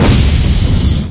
scanner
cbot_discharge2.ogg